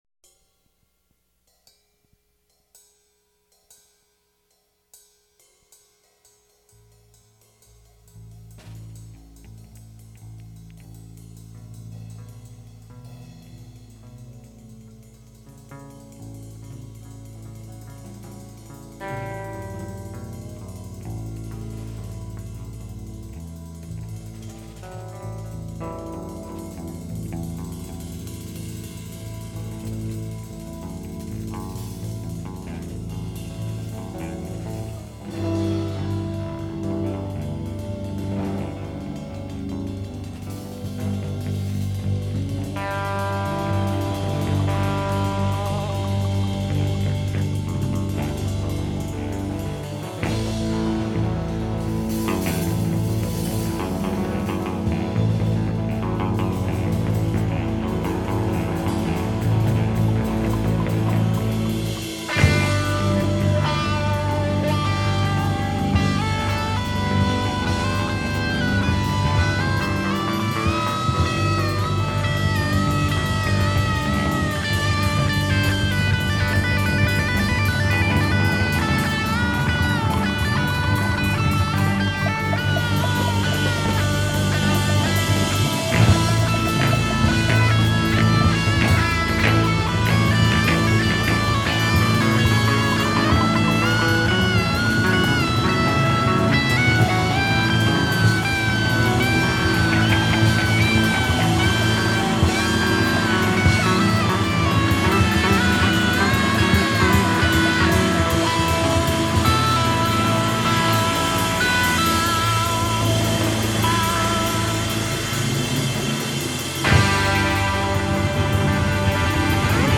Groove